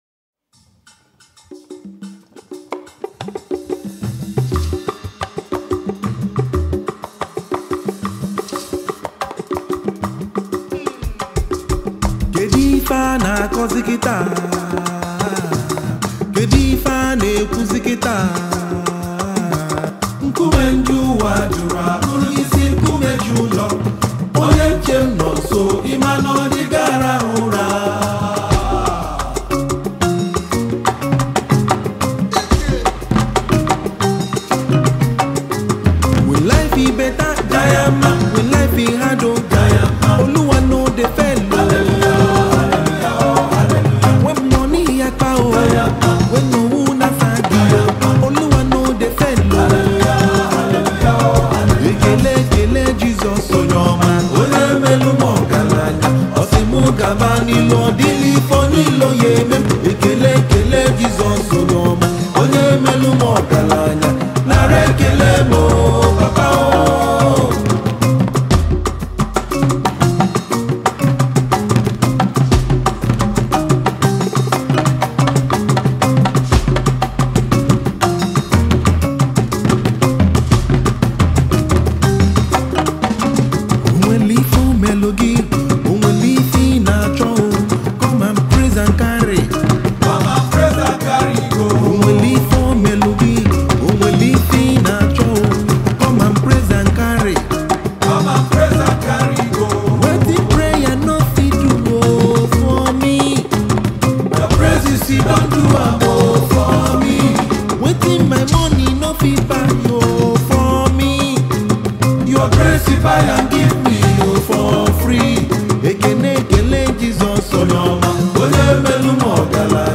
Gospel
songwriter and saxophonist.
music comes heavily blended in traditional African rhythms